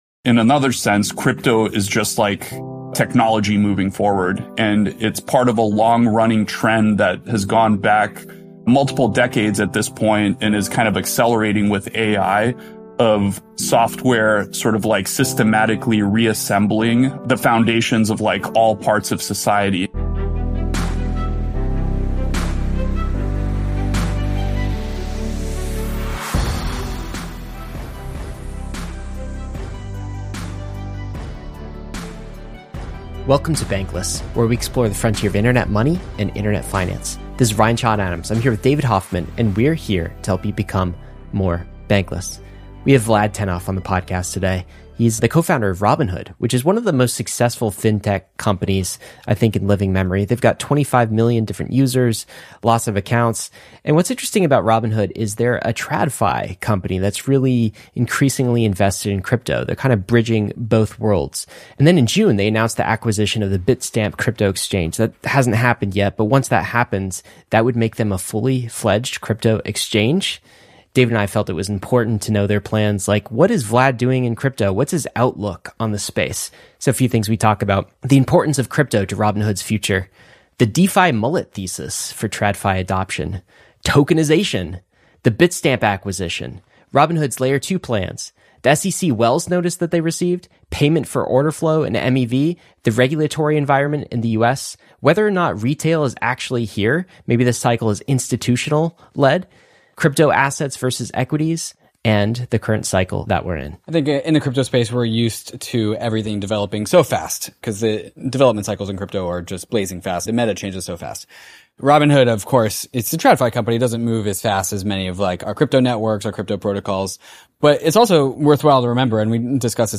We brought the co-founder Vlad Tenev and we had a wide-ranging conversation covering topics like tokenization, Robinhood’s Wallet and L2, payment for orderflow and MEV, the SEC Wells Notice and much more.